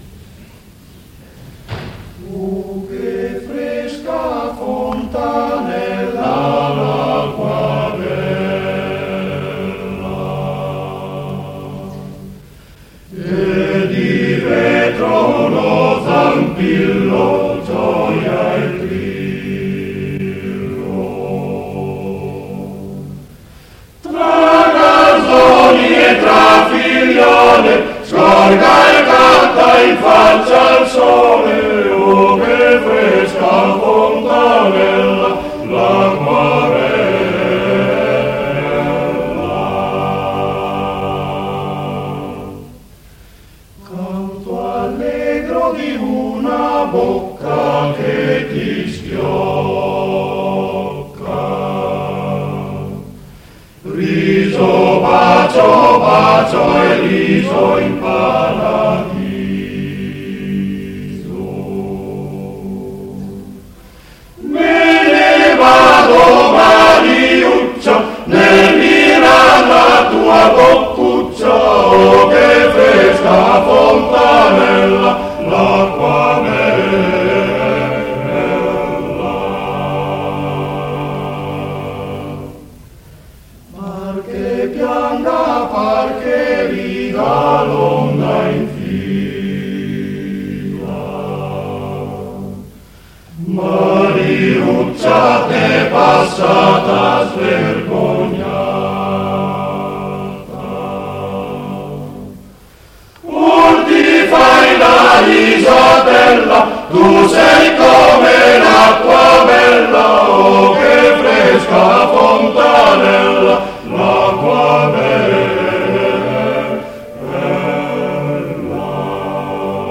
Torino, Conservatorio G. Verdi, 8 dicembre 1956